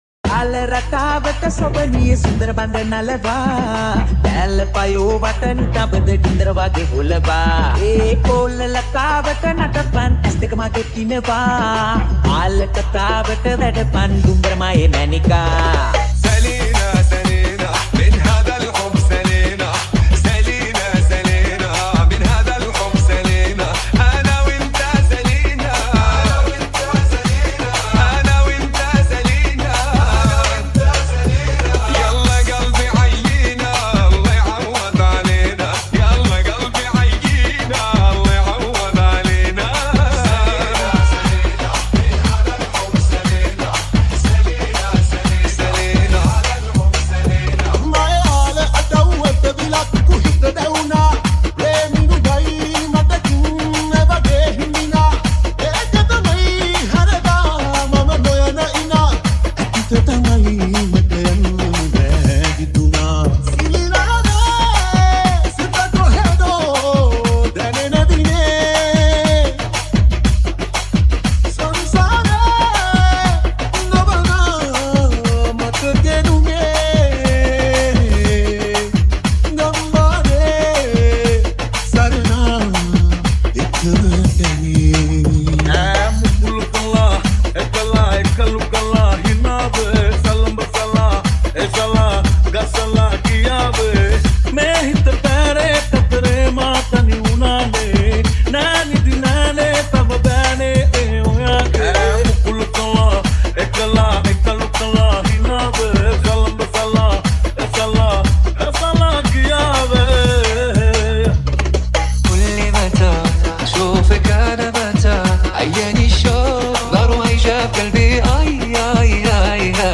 High quality Sri Lankan remix MP3 (10).